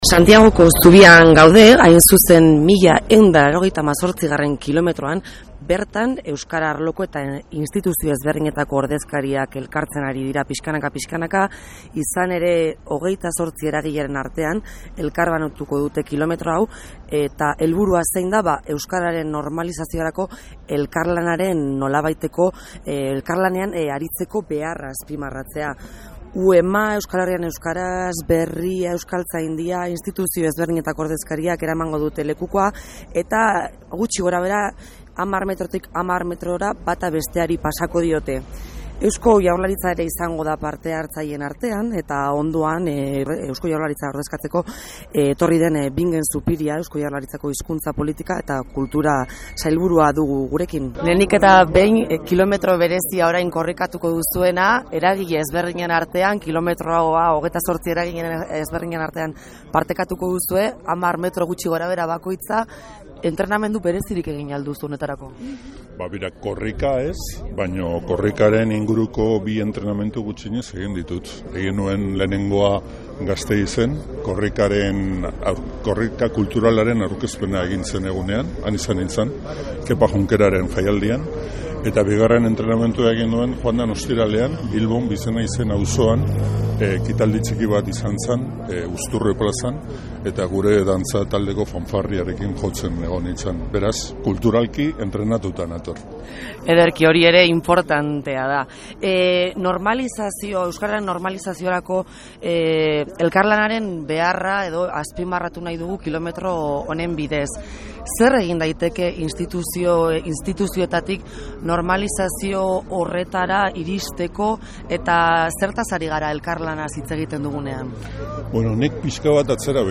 Korrika Santiagoko Zubira heldu baino minutu batzuk lehenago, Eusko Jaurlaritzako Hizkuntza, Politika eta Kultura Sailburua den Bingen Zupiriarekin labur hitzegiteko aukera izan dugu. Arrosa Sarea euskaraz emititzen duten irratien sarea izanik, irrati lizentzien aferaren inguruan galdetzeko ere aprobetxatu dugu.
Bingen-Zupiria-elkarrizketa.mp3